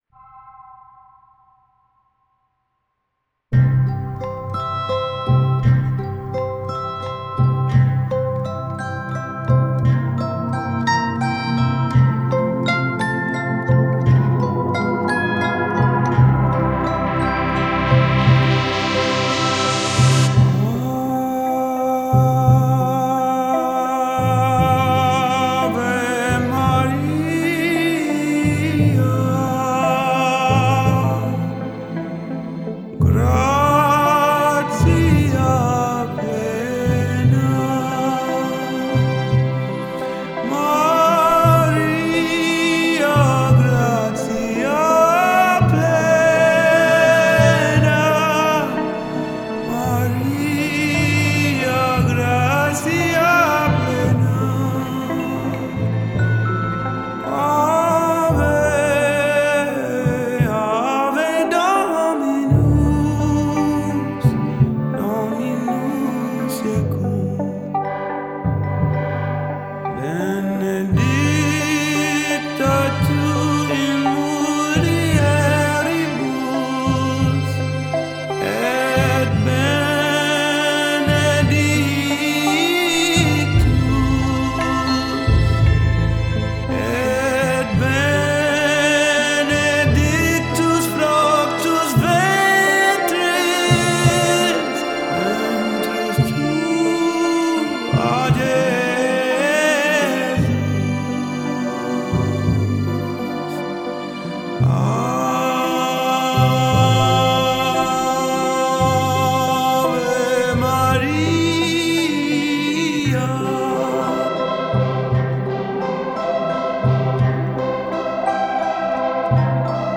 выполненная в жанре соул и поп.